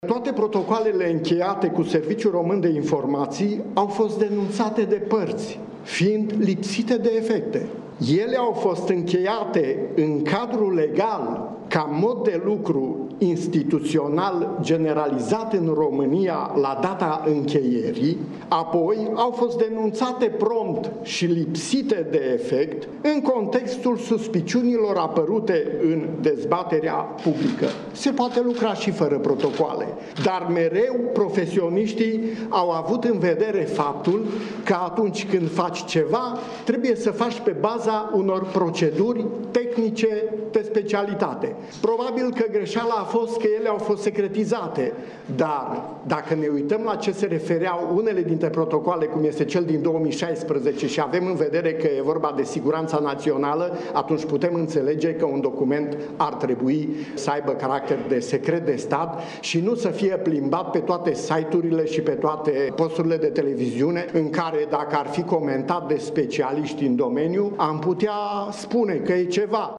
Procurorul general Augustin Lazăr precizează: